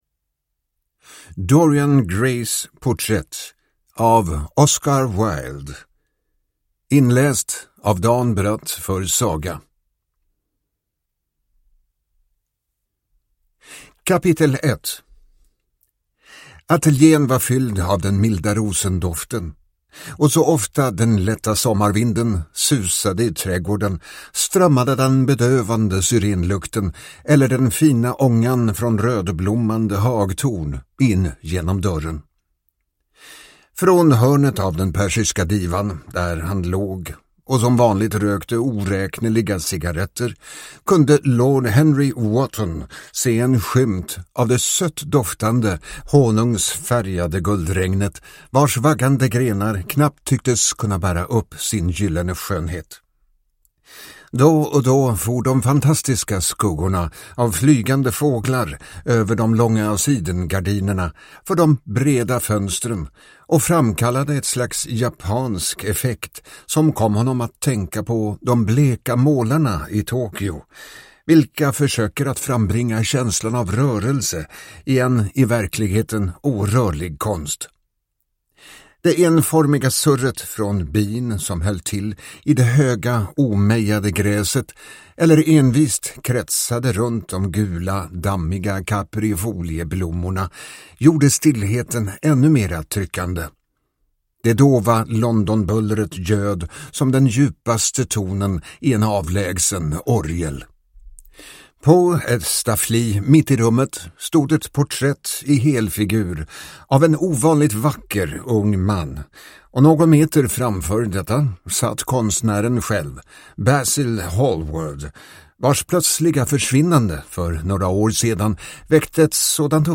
Dorian Grays porträtt / Ljudbok